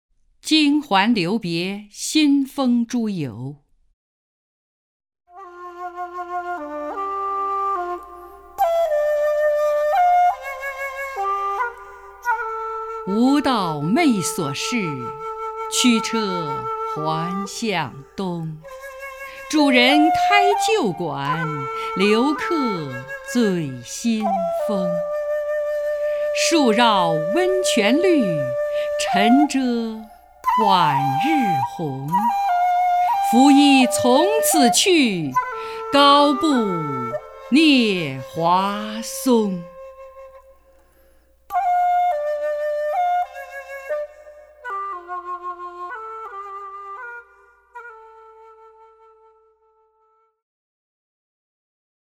首页 视听 名家朗诵欣赏 张筠英